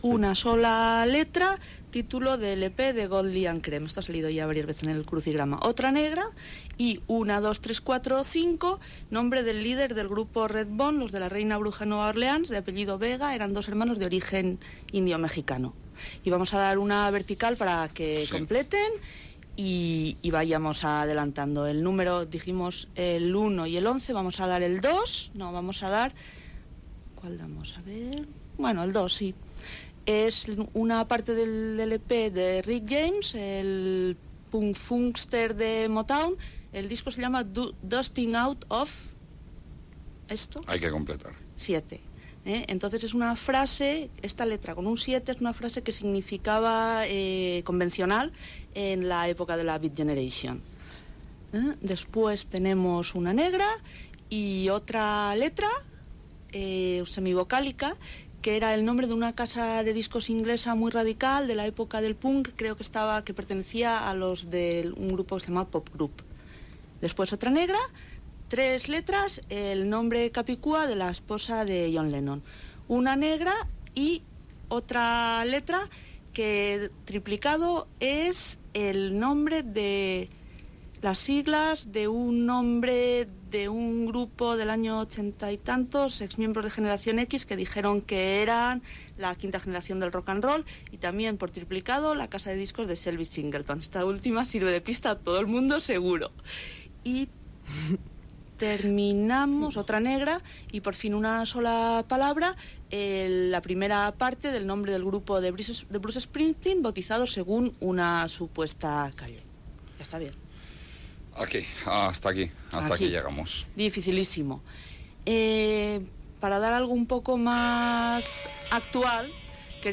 Mots encreuats relacionats amb la música, especial de la revista "Ajo Blanco", tema musical, comentari sobre el Nadal, publicitat, quatre temes musicals, adreça electrònica del programa i correu electrònic rebut, tema musical, reflexió de la setmana sobre l'assassinat del regidor de Renteria José Luis Caso per ETA el dia anterior, publicitat, indicatiu del programa i tema musical
Entreteniment